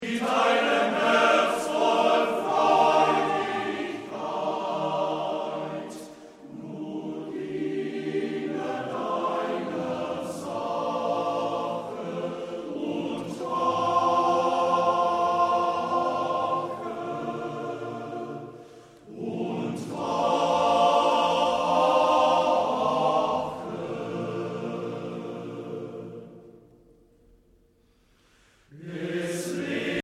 Trostvoll, harmonisch und warm